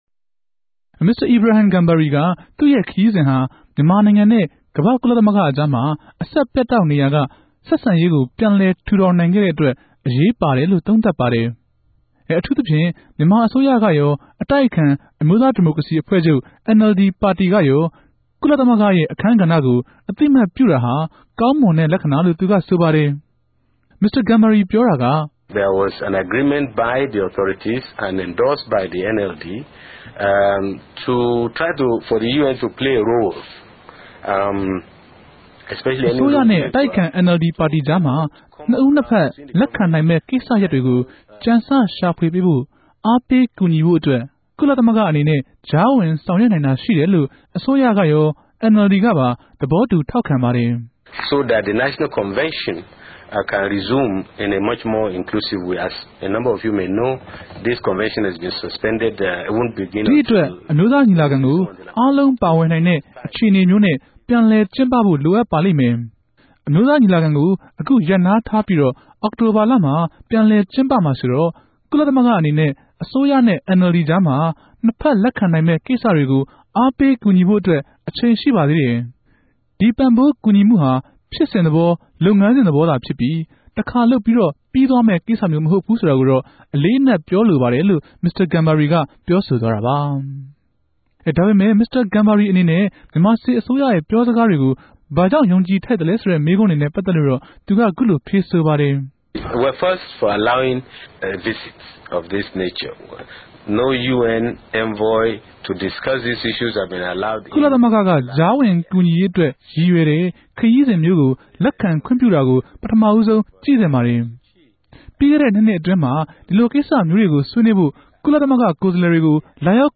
မင်္စတာ ဂမ်ဘာရီက ဒေၞအောင်ဆန်းစုုကည် ကဵန်းမာေုကာင်း ခုလို ေူပာဆိုပၝတယ်။